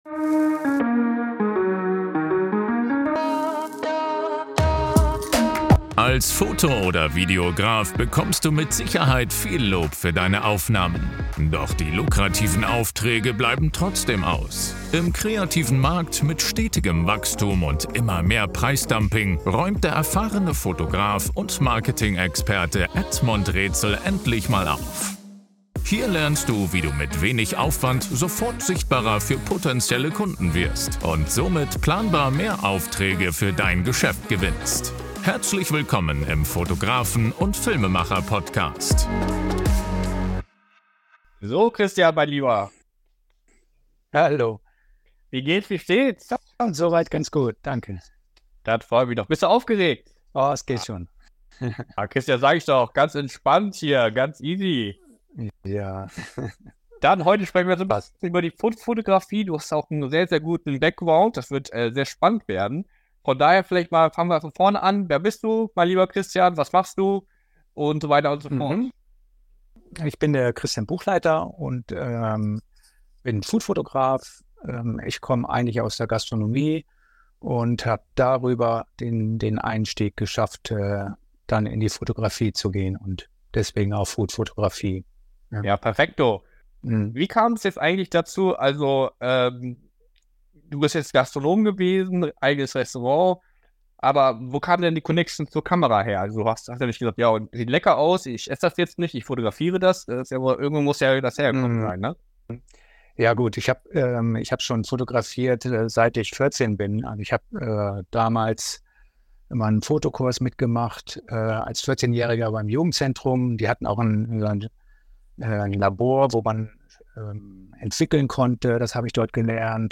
#357 - Foodfotografie Tipps & Tricks - Interview